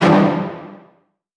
Scare_v5_wav.wav